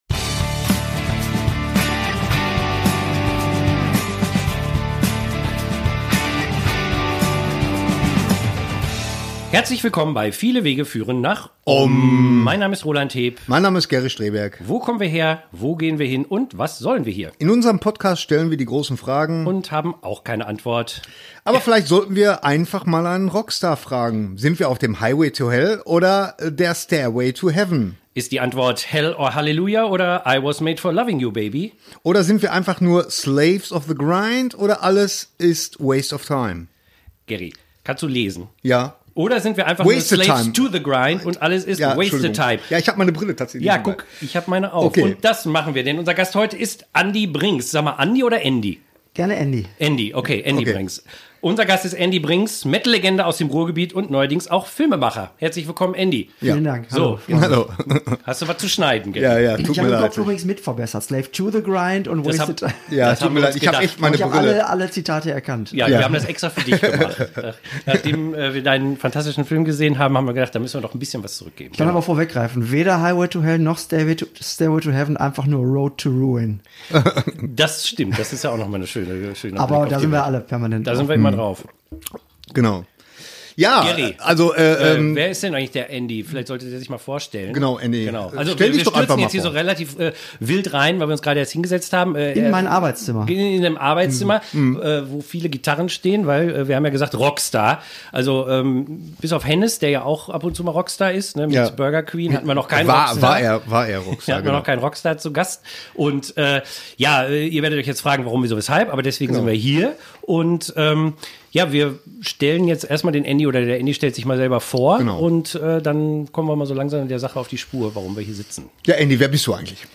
Episode 56 - der Kreis schließt sich - ein Gespräch